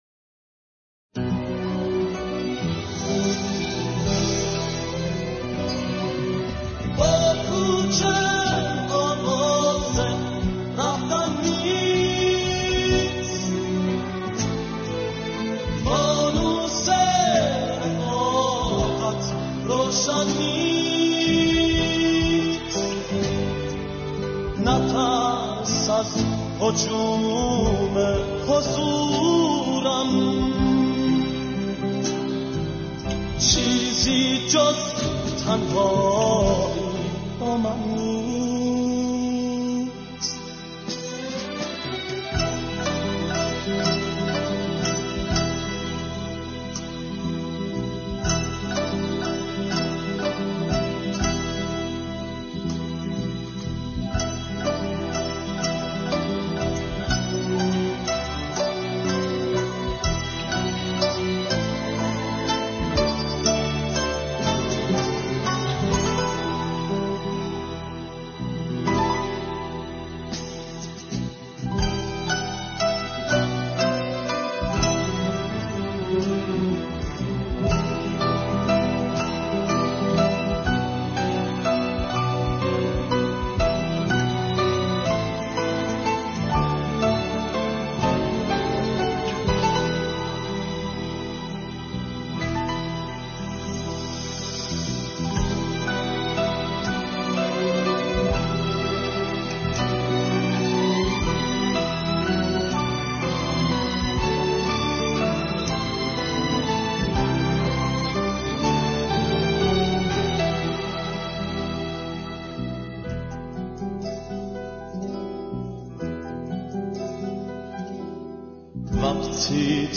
موزیک دهه شصتی